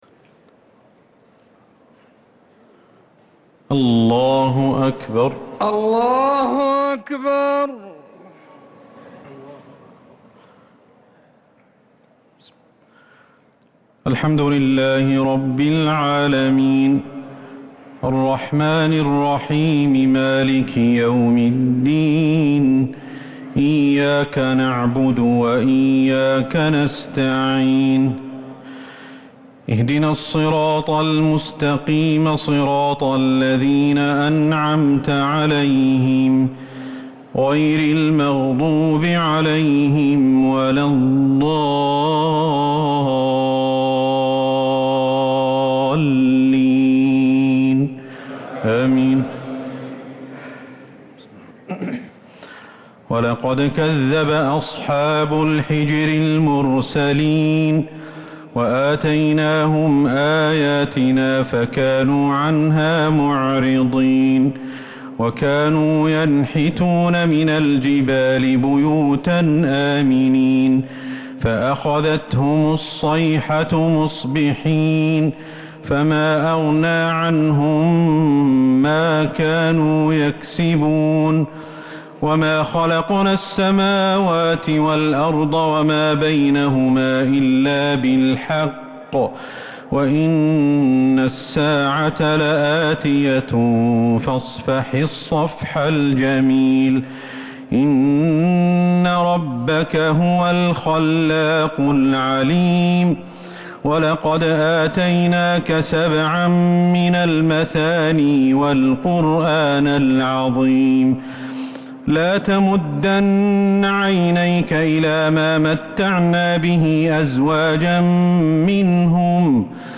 صلاة التراويح ليلة 18 رمضان 1443 للقارئ أحمد الحذيفي - التسليمتان الأخيرتان صلاة التراويح